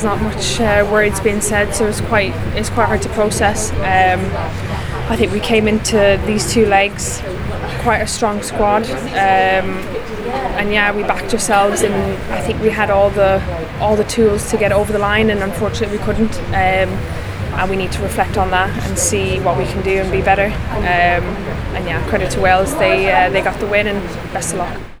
Ireland midfielder Megan Connolly says the squad are devastated to not be going to next summer’s Euros in Switzerland…